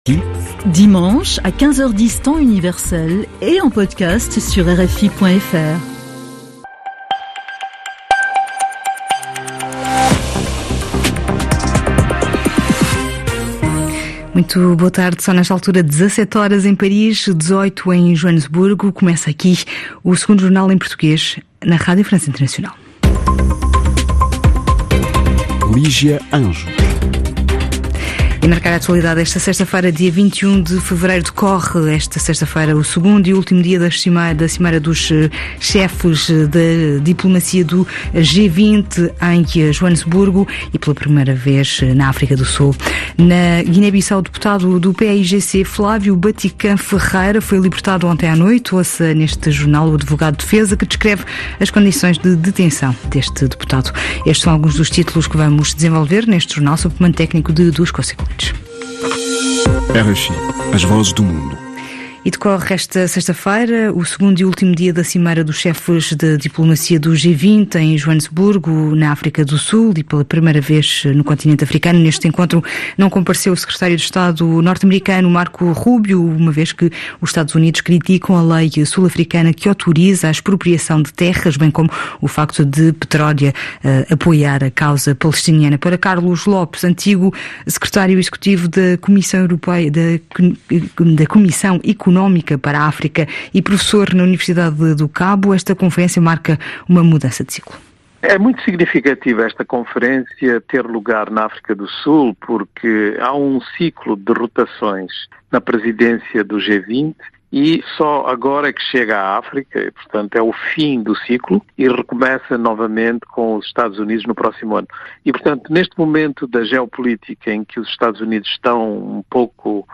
Ouça o jornal